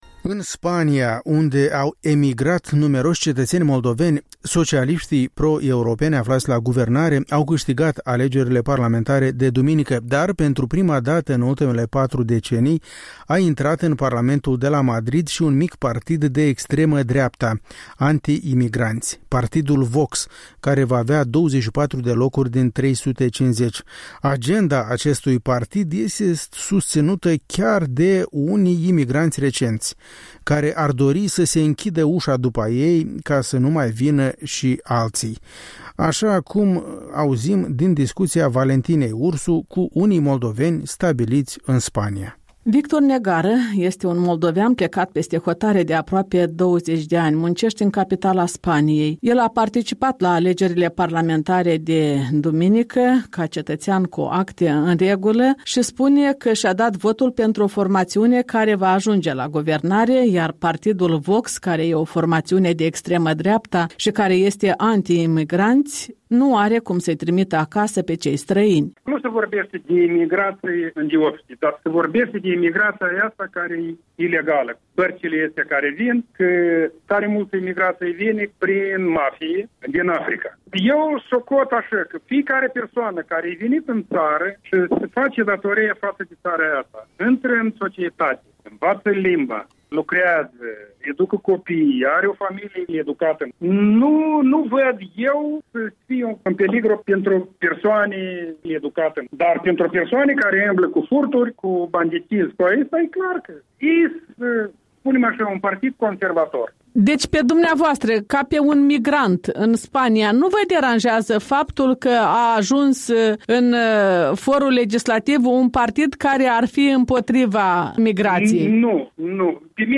Opiniile a doi moldoveni stabiliți în Spania de aproape două decenii.